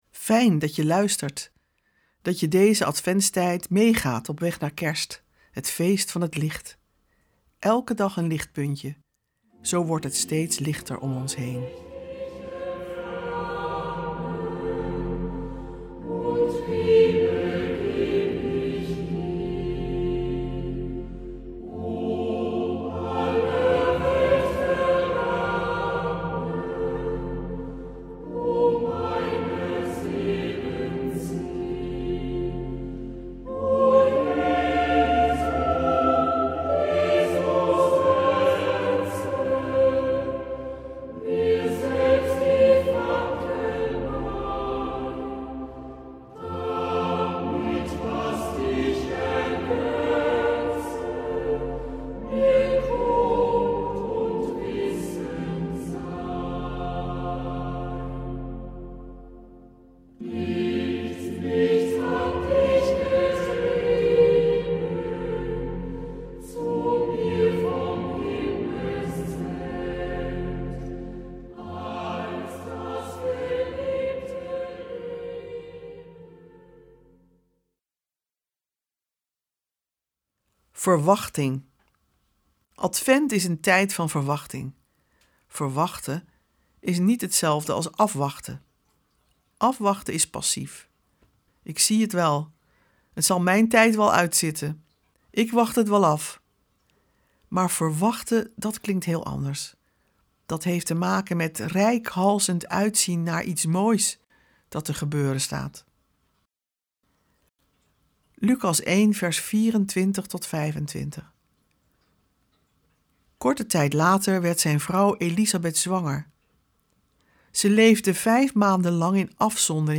Luister vandaag naar het verhaal van Elisabeth en de verwachtingsvolle muziek van Beethoven…